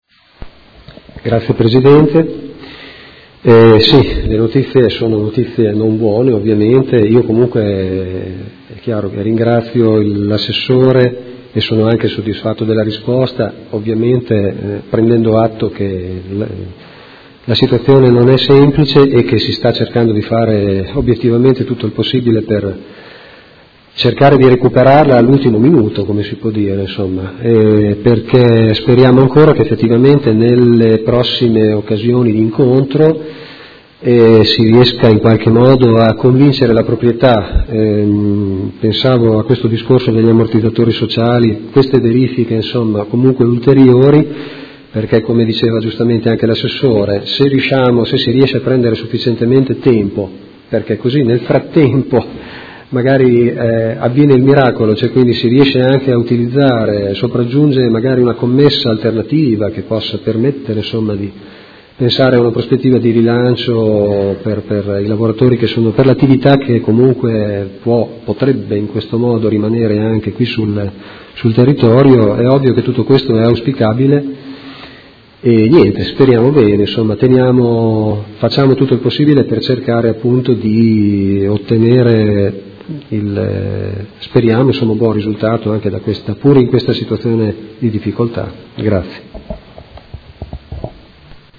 Seduta del 22/11/2018. Conclude interrogazione dei Consiglieri Malferrari e Trande (Art1-MDP/Per Me Modena) avente per oggetto: La Edis comunica ai dipendenti il trasferimento della sede aziendale da Modena a Villa Marzana (Rovigo)